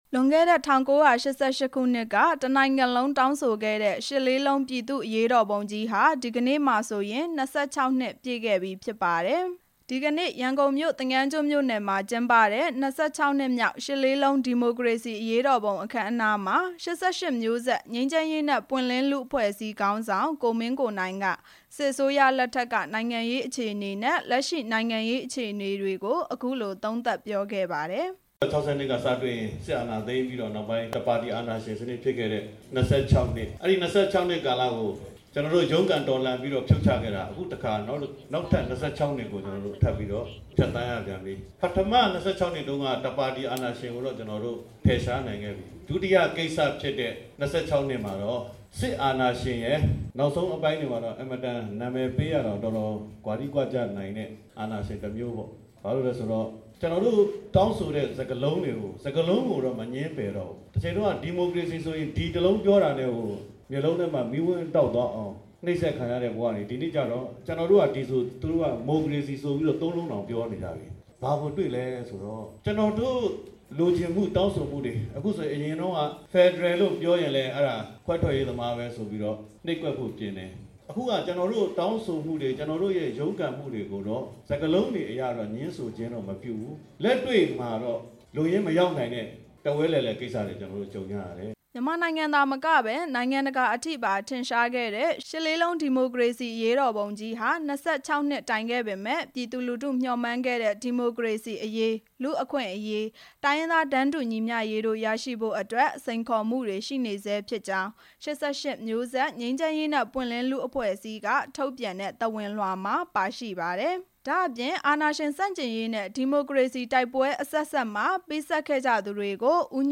ရန်ကုန်မြို့ သင်္ယန်းကျွန်းမြို့နယ် ကျိုက္ကဆံဓမ္မပိယ သာသနာ့ရိပ်သာမှာကျင်းပတဲ့ အခမ်းအနားမှာ ကိုမင်းကိုနိုင်က အခုလိုပြောခဲ့တာဖြစ်ပါတယ်။